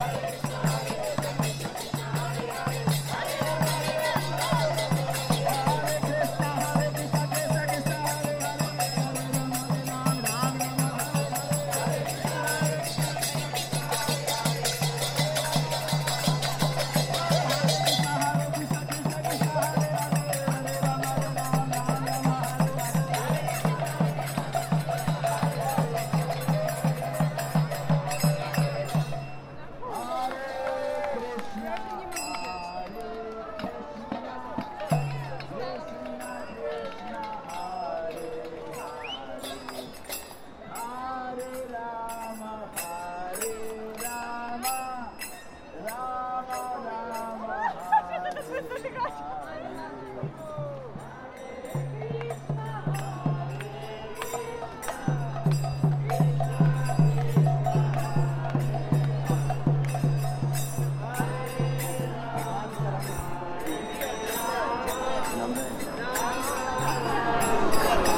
Hare Krishna dancing in Milan